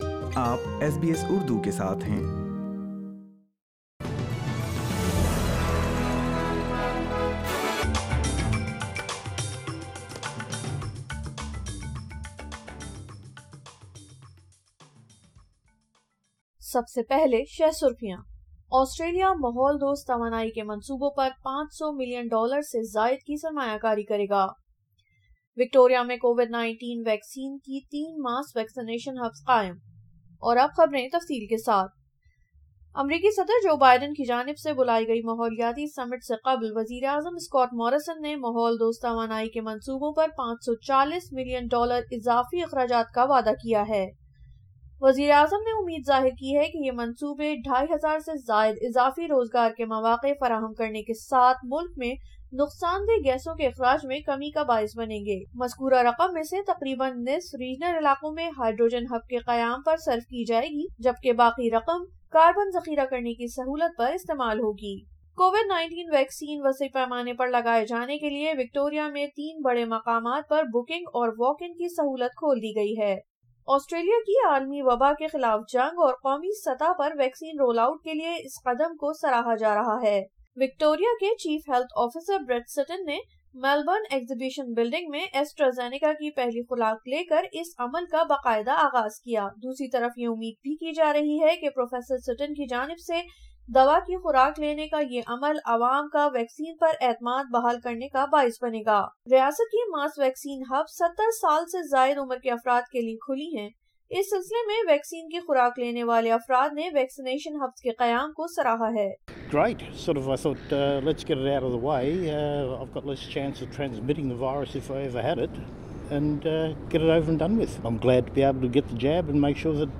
اردو خبریں 21 اپریل 2021